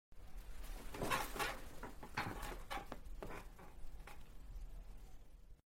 Tiếng Ngồi Xuống Ghế văn phòng
Thể loại: Tiếng đồ vật
Description: Tiếng ngồi xuống ghế văn phòng, âm thanh ghế kêu, tiếng va chạm ghế, tiếng cọt kẹt ghế, sound effect ngồi xuống ghế là hiệu ứng âm thanh mô phỏng khoảnh khắc ai đó ngồi xuống ghế làm việc. Âm thanh này thường gồm tiếng quần áo cọ xát, tiếng bánh xe ghế di chuyển nhẹ, hoặc tiếng kim loại và nhựa va chạm.
tieng-ngoi-xuong-ghe-van-phong-www_tiengdong_com.mp3